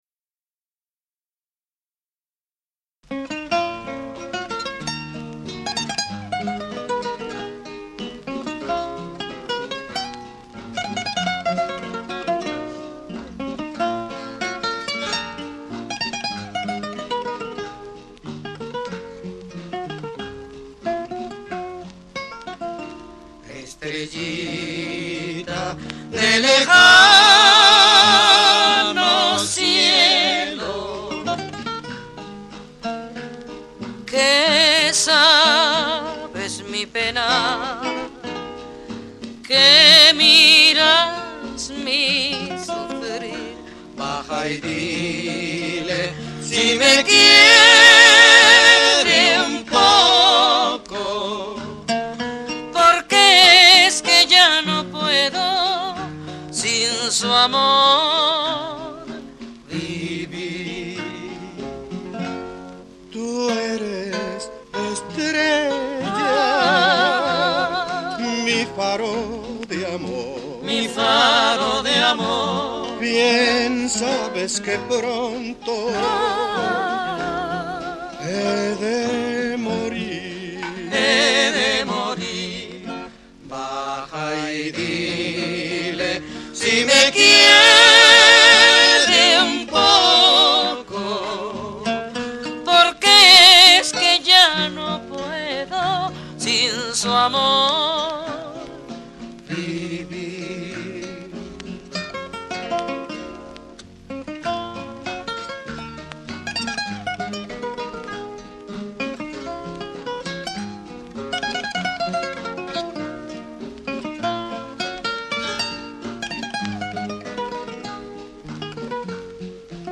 High Fidelity recording